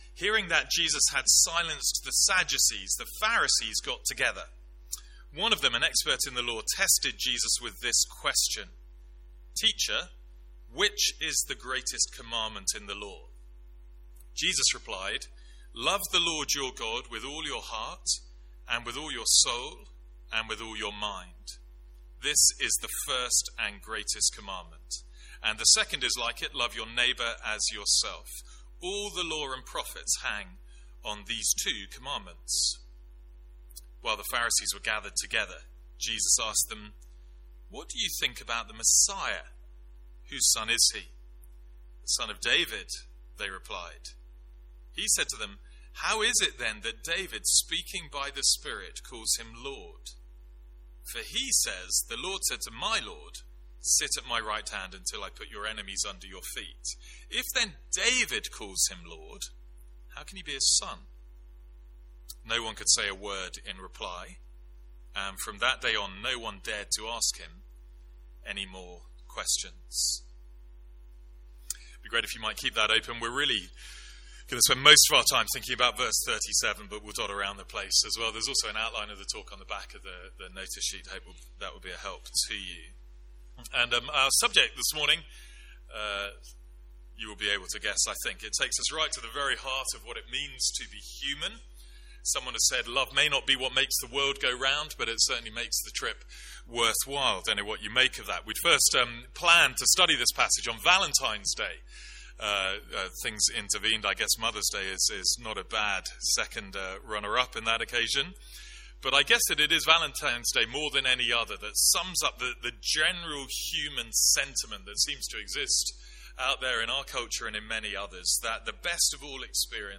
From the morning service on Giving Sunday 2016.